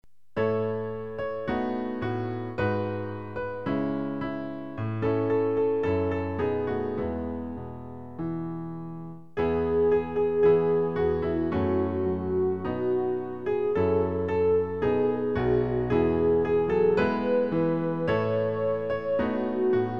Klavier-Playback zur Begleitung der Gemeinde
MP3 Download (ohne Gesang)